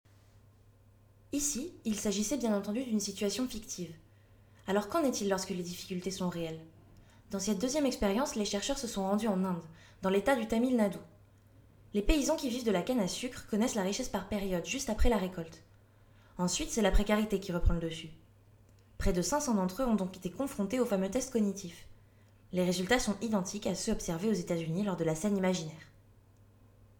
extrait voix
19 - 40 ans - Mezzo-soprano